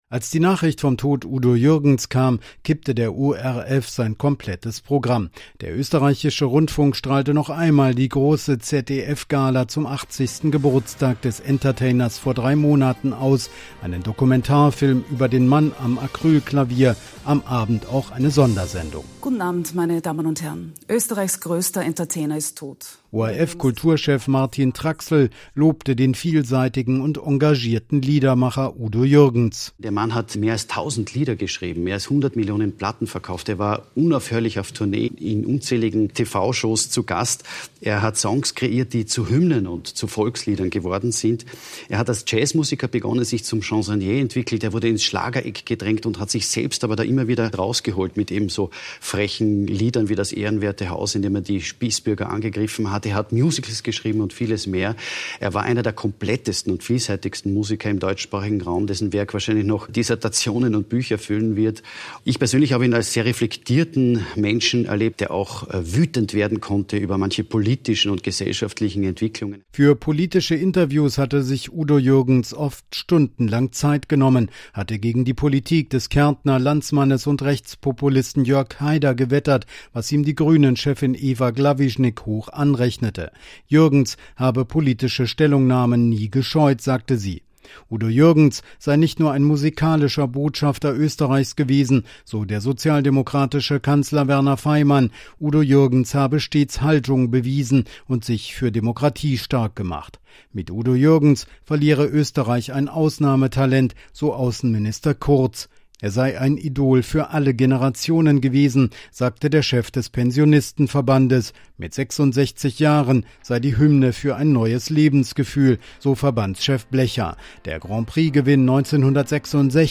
Udo Jürgens tot – Pressespiegel Österreich